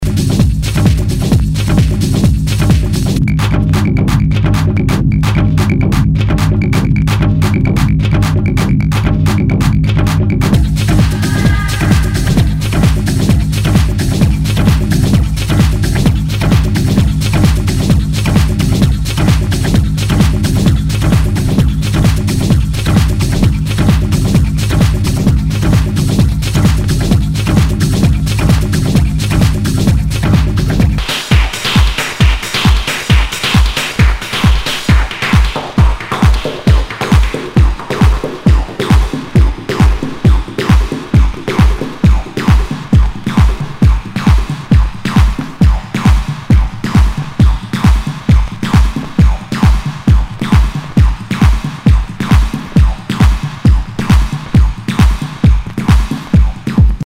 HOUSE/TECHNO/ELECTRO
ナイス！テック・ハウス！
盤に傷あり、全体にチリノイズが入ります